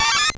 Cri de Pichu dans Pokémon Or et Argent.